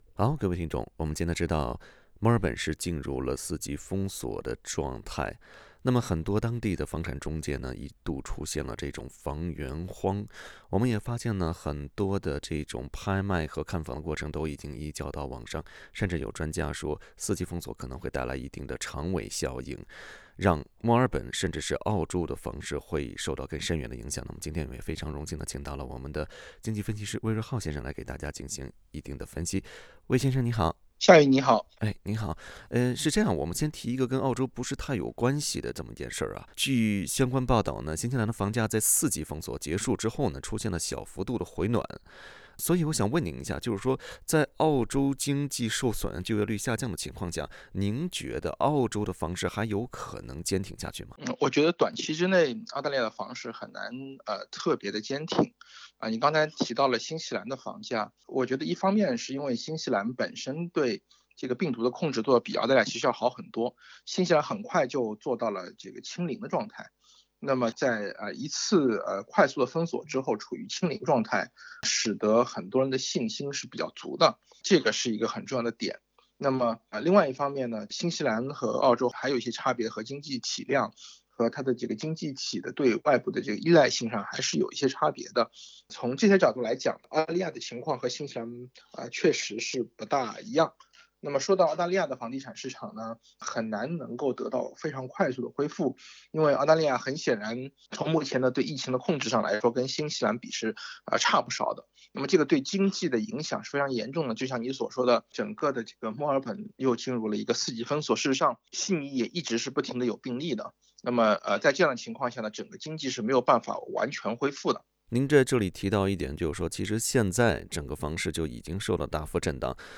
经济分析师为您纤细解读。（欢迎点击图片音频，收听采访）。